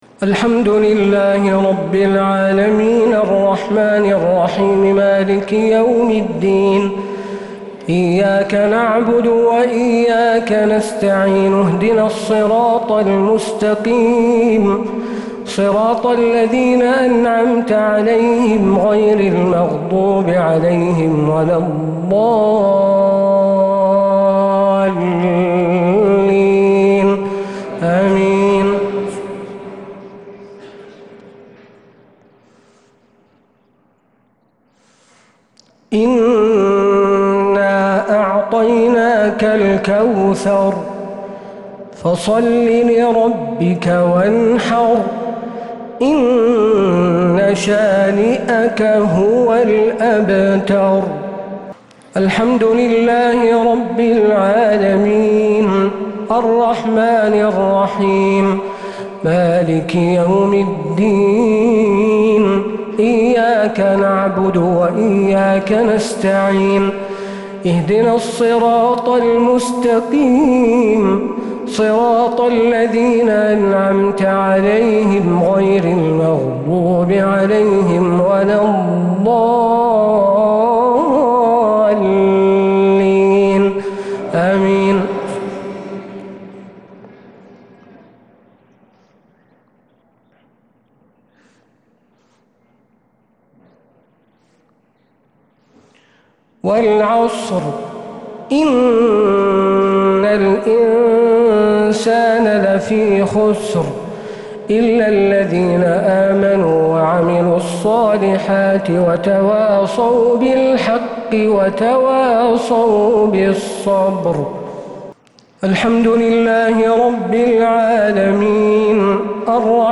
صلاة الشفع و الوتر ليلة 2 رمضان 1446هـ | Witr 2nd night Ramadan 1446H > تراويح الحرم النبوي عام 1446 🕌 > التراويح - تلاوات الحرمين